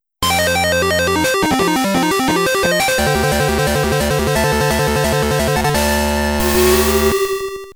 magnettrain.wav